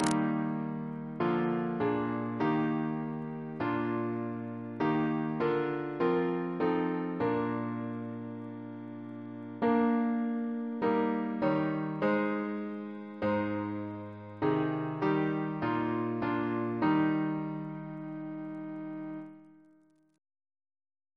CCP: Chant sampler
Double chant in E♭ Composer: William Boyce (1710-1779), Organist and Composer to the Chapel Royal Reference psalters: PP/SNCB: 117